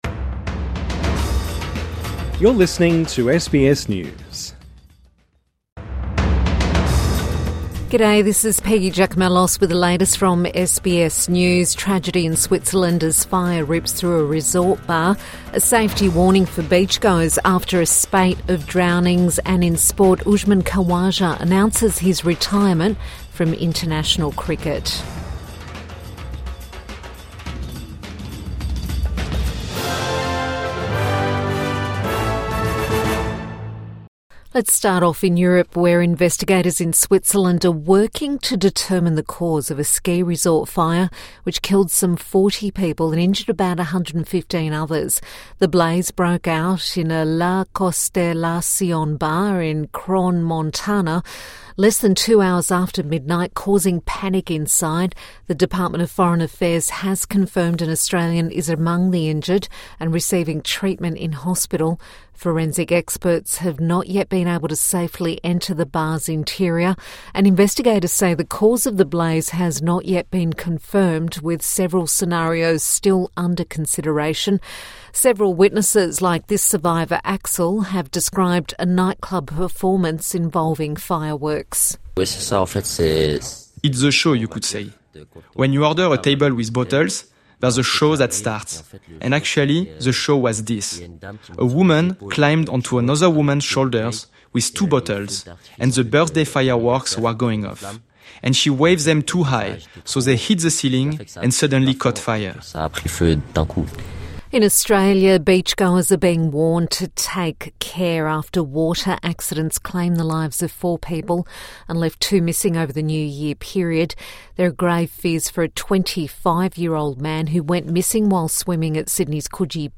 Tragedy in Switzerland as fire rips through a resort bar | Midday News Bulletin 2 January 2026